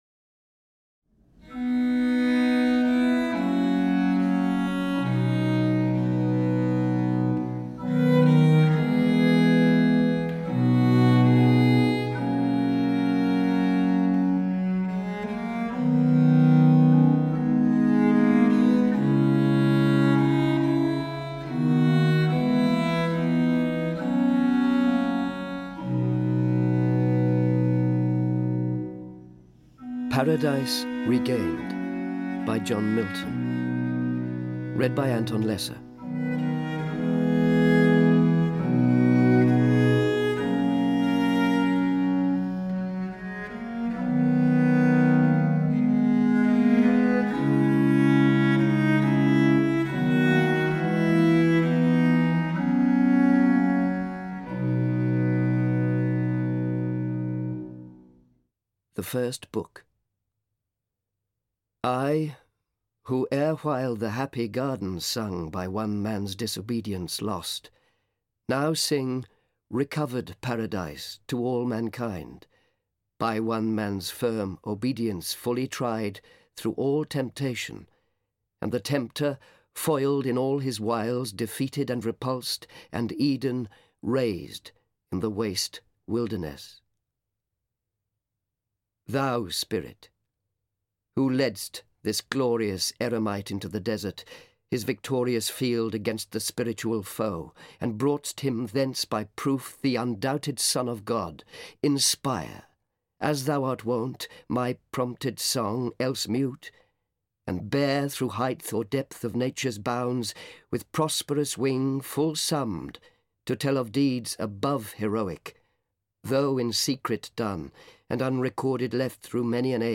Paradise Regained (EN) audiokniha
Ukázka z knihy
• InterpretAnton Lesser